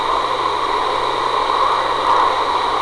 EVP's
This is from  4-5-02 at a local cemetery.